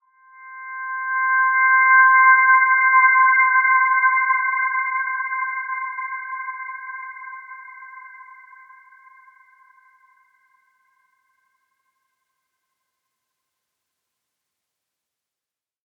Dreamy-Fifths-C6-f.wav